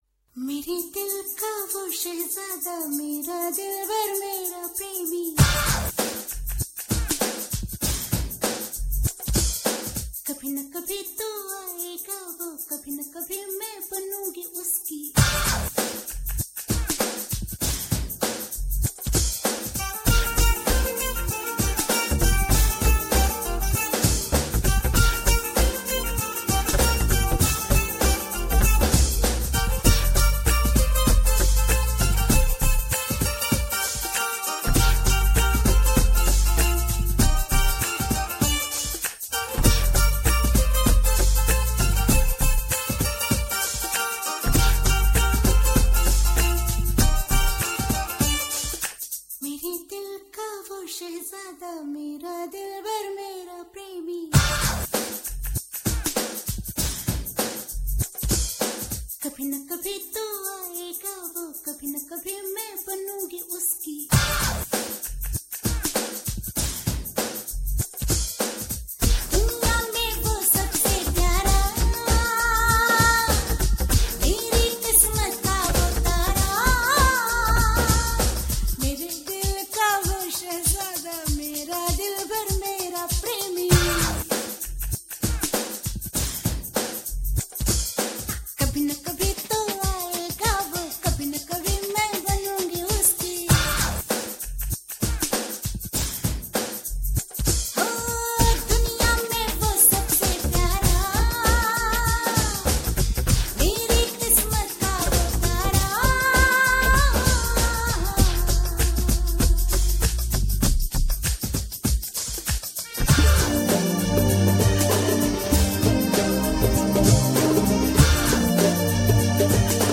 Bollywood track